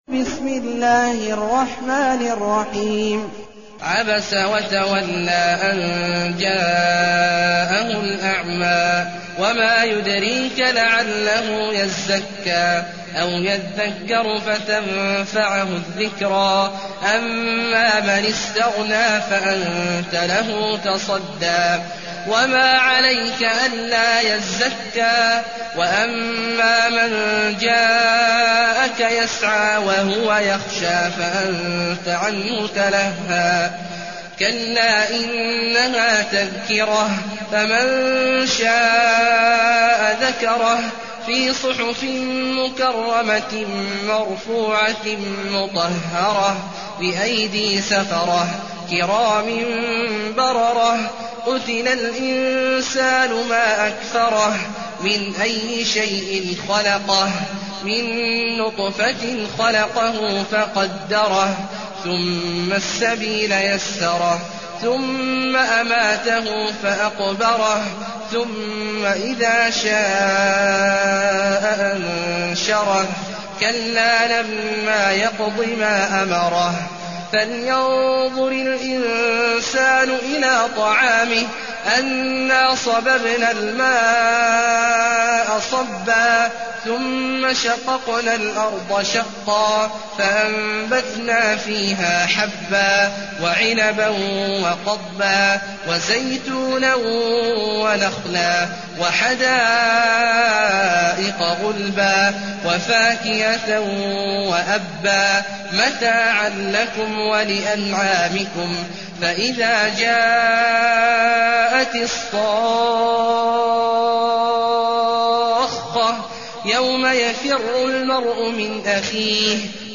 المكان: المسجد النبوي الشيخ: فضيلة الشيخ عبدالله الجهني فضيلة الشيخ عبدالله الجهني عبس